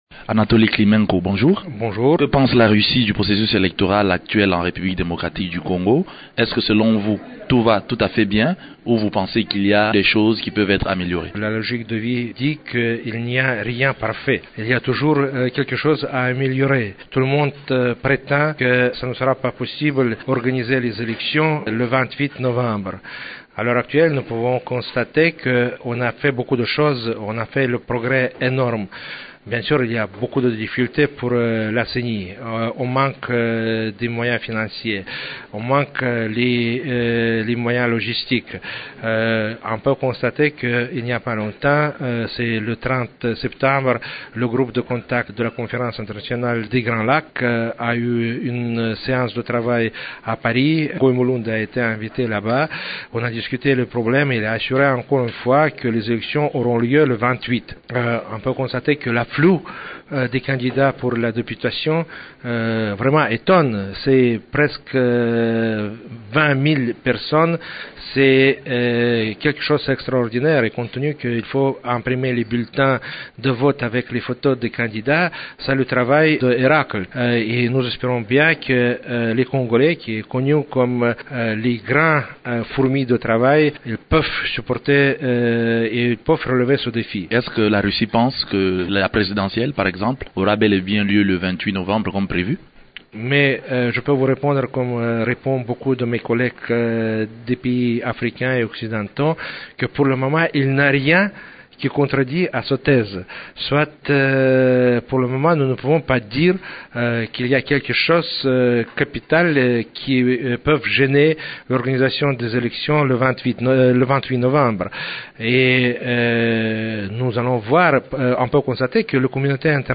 Radio Okapi reçoit l’ambassadeur de la Fédération de Russie en République Démocratique du Congo. Dans une interview accordée à notre station, M. Anatoly Klimenko revient notamment sur le processus électoral en RDC.